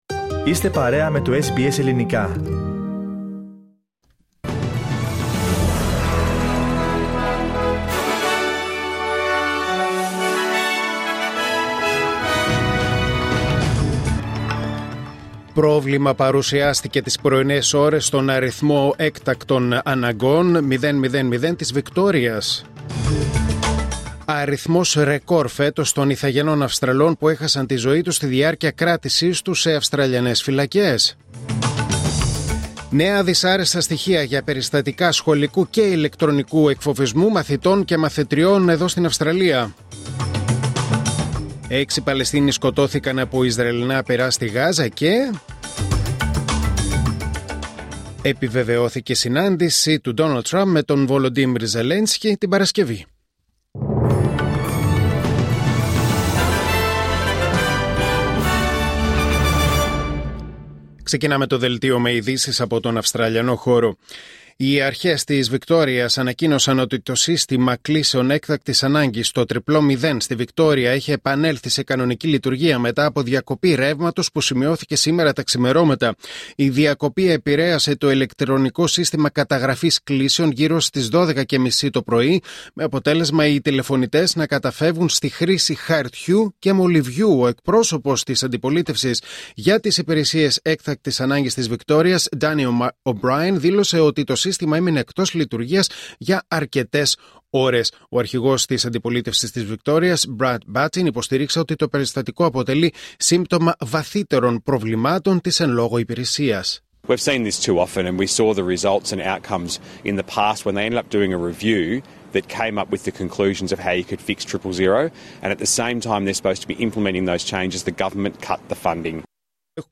Δελτίο Ειδήσεων Τετάρτη 15 Οκτωβρίου 2025